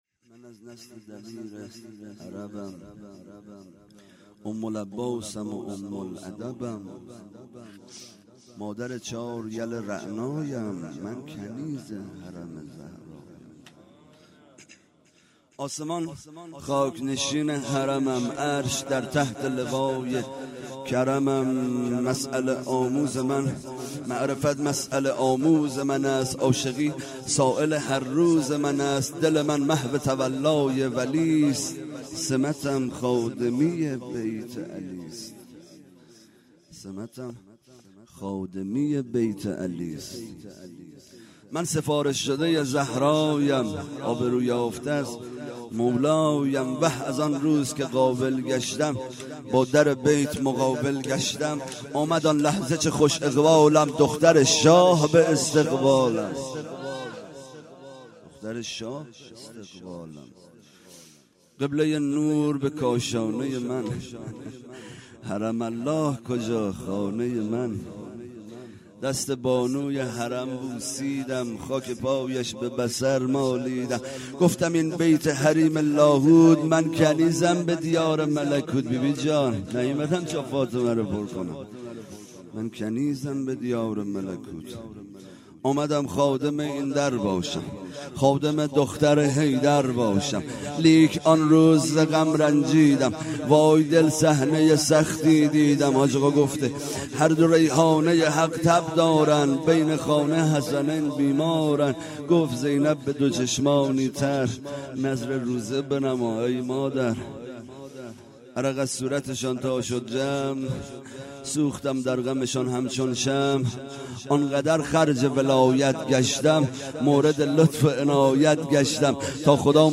هیئت زواراباالمهدی(ع) بابلسر
0 0 مدح و روضه
وفات حضرت ام البنین س